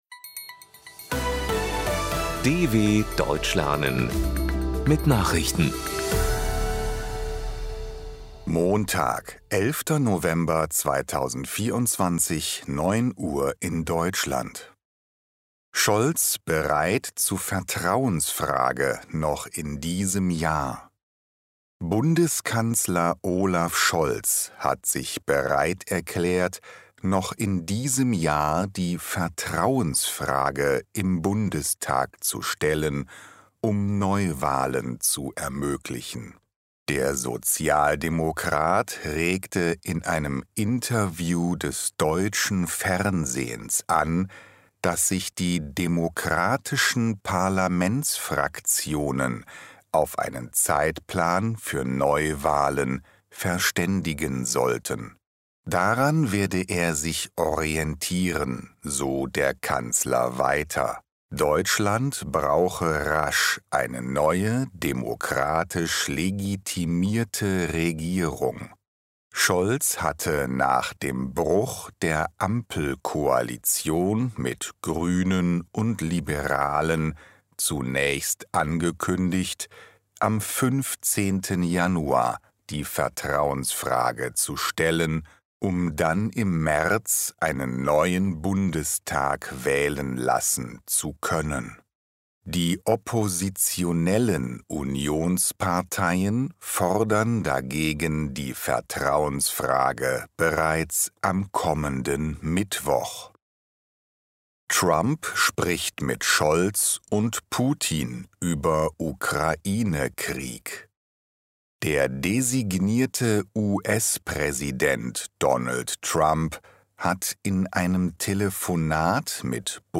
11.11.2024 – Langsam Gesprochene Nachrichten
Trainiere dein Hörverstehen mit den Nachrichten der Deutschen Welle von Montag – als Text und als verständlich gesprochene Audio-Datei.